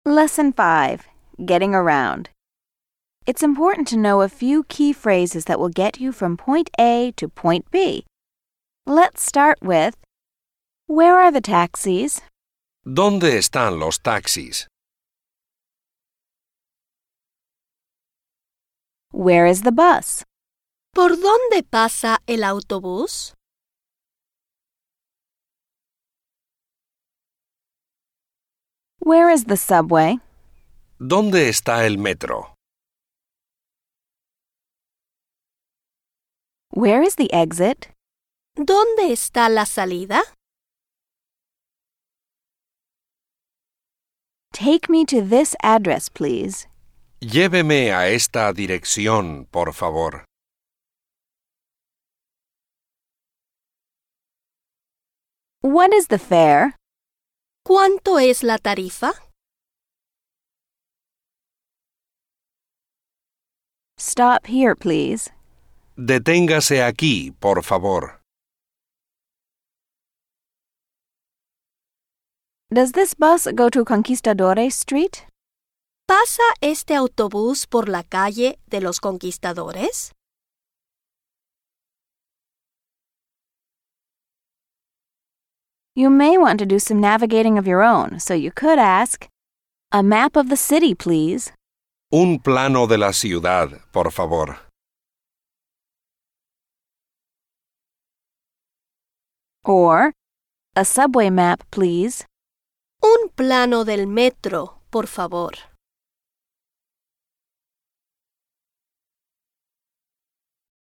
# Simple and effective format - just listen and repeat.